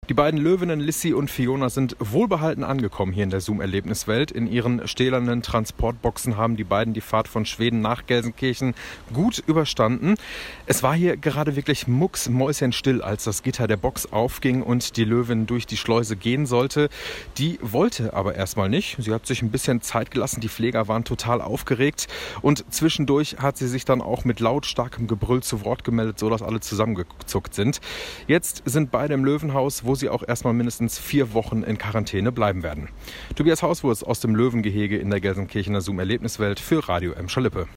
ankunft-loewinnen-live-aus-dem-gehege.mp3